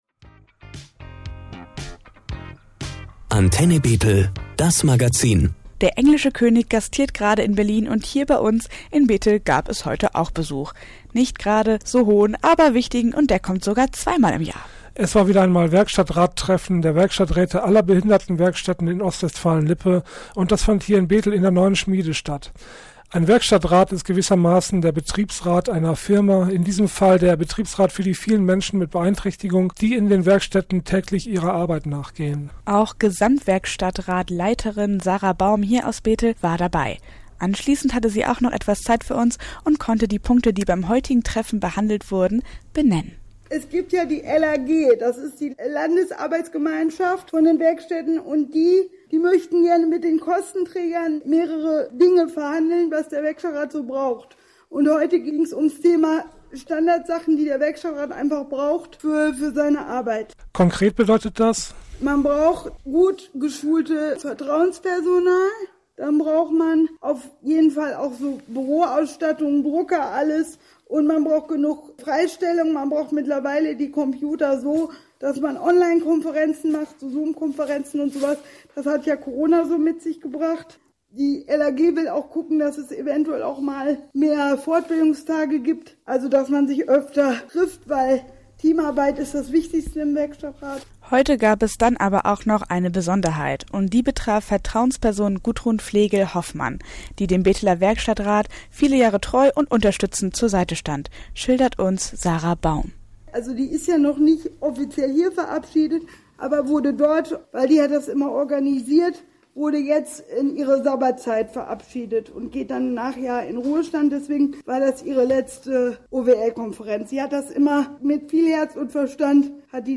Antenne Bethel stand sie Rede und Anwort direkt nach der Veranstaltung: